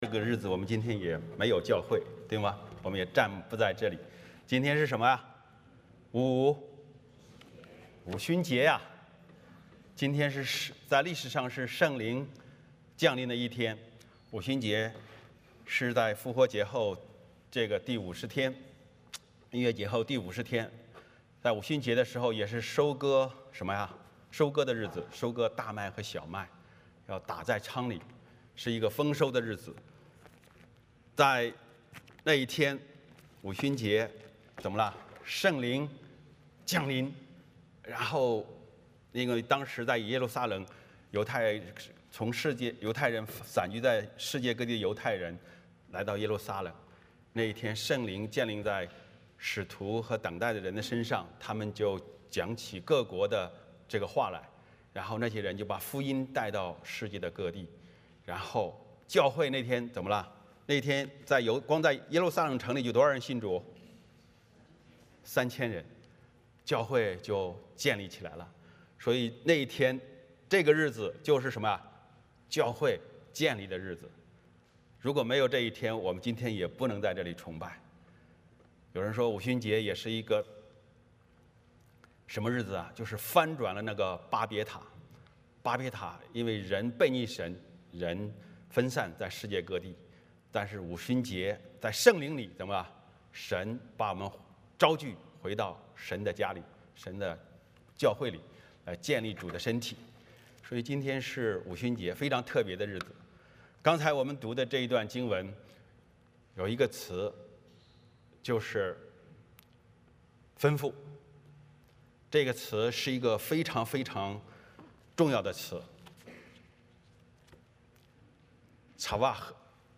创世记 2:4-17 Service Type: 主日崇拜 欢迎大家加入我们的敬拜。